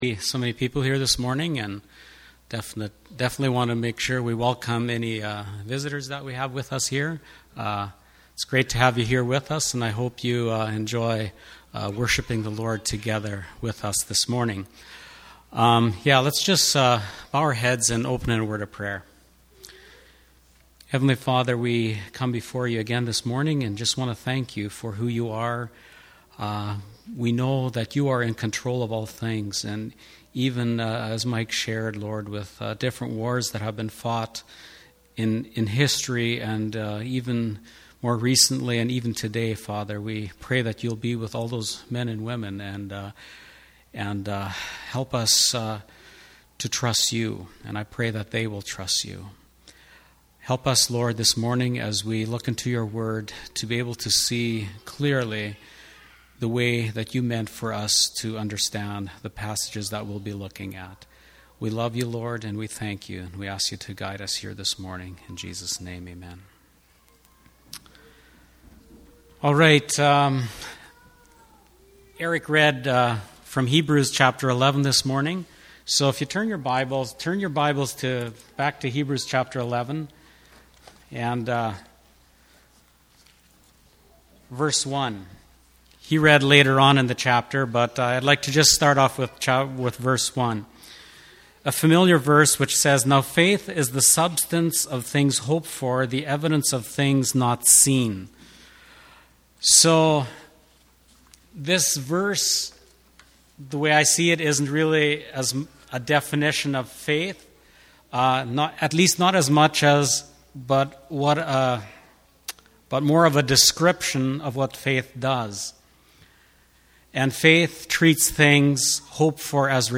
Hebrews 11:1-12:2 Service Type: Sunday Morning « ABF